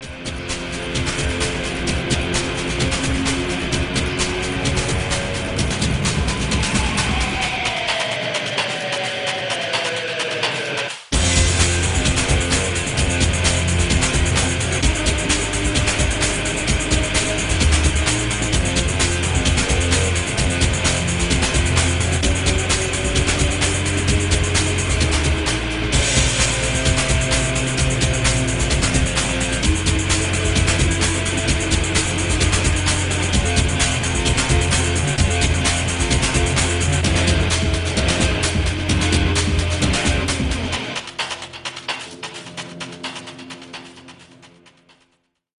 • Piste 10  -  [1'46]  -  Comme la 02 : bonne zik d'action !